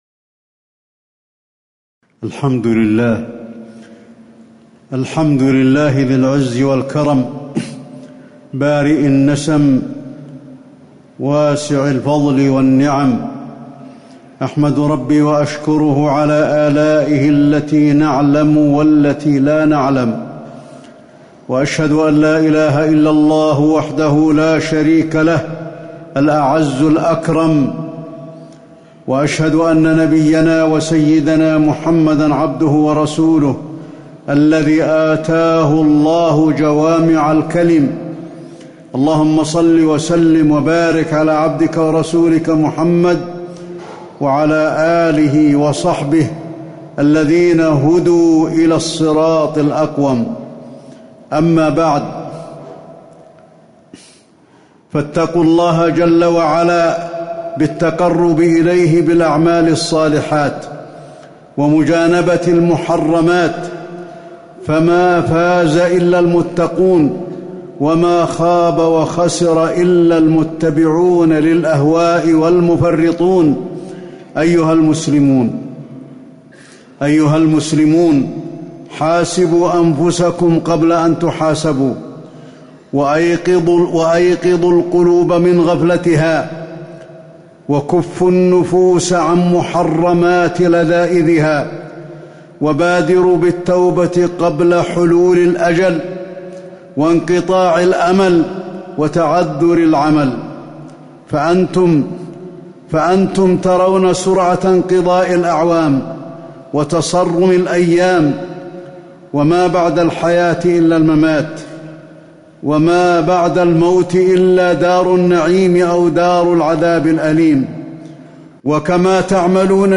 تاريخ النشر ١٥ ربيع الأول ١٤٤٠ هـ المكان: المسجد النبوي الشيخ: فضيلة الشيخ د. علي بن عبدالرحمن الحذيفي فضيلة الشيخ د. علي بن عبدالرحمن الحذيفي الدين النصيحة